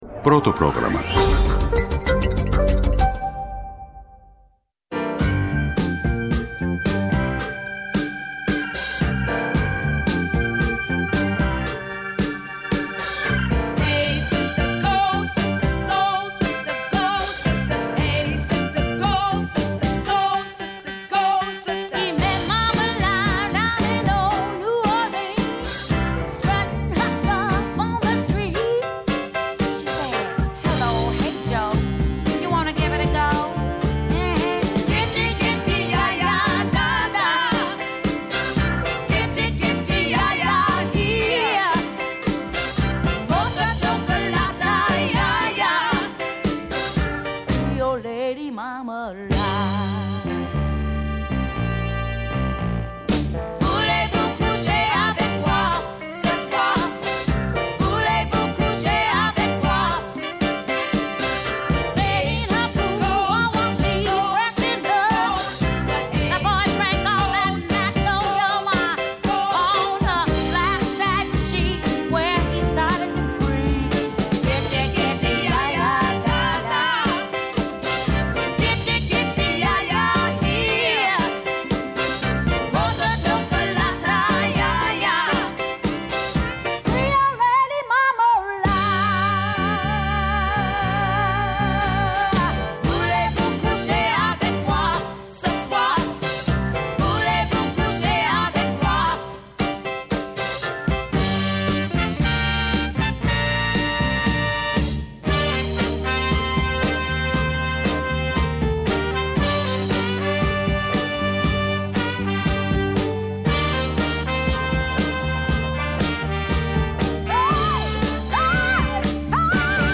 αγαπημένα τραγούδια από το 1975